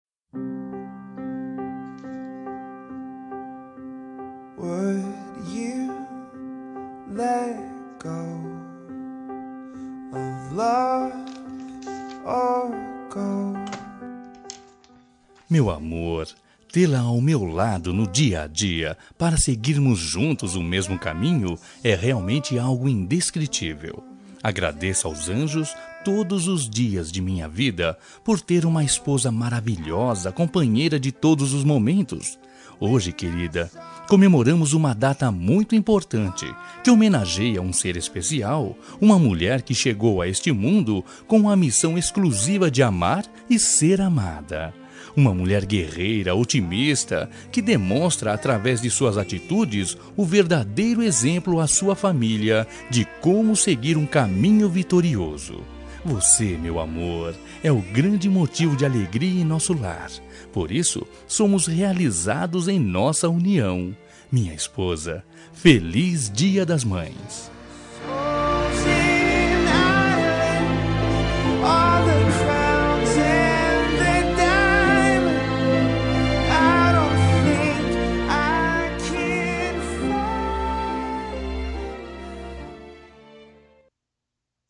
Dia das Mães – Para Esposa – Voz Masculina – Cód: 6531